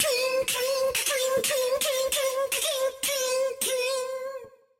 (Schwertgeräusche)